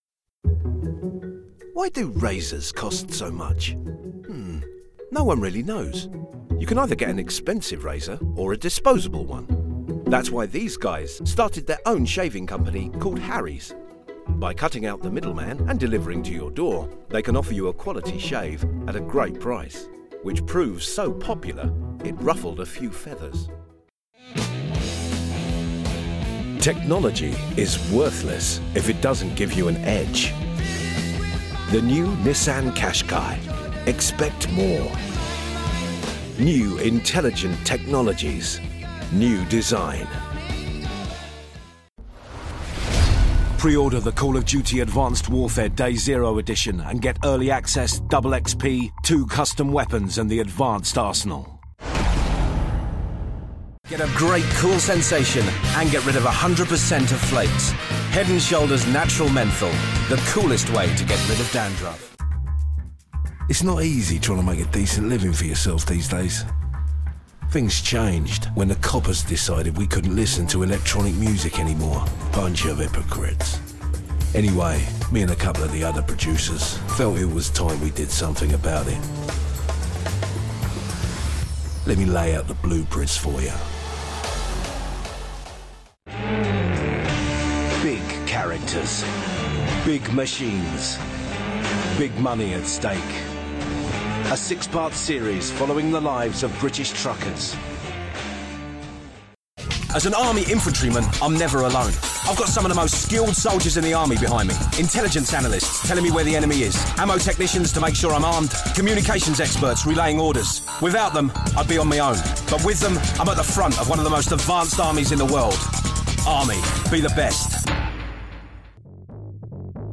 Voiceover Artist,
Sex: Male
Languages: English British,
Accents: ACCENT SPECIALIST. CONFIDENT WITH MOST ACCENTS
Ages Performed: Middle Age,
Voiceover Genre: Animation, Audiobook, Business, Character, Commercial, Corporate, Documentary, E-Learning, Explainer, Industrial, IVR, Medical, Narration, Promo, Trailer, Video Games, Telephony/IVR, ADR/dubbing,
Neumann TLM 103, Focusrite Scarlett 6i6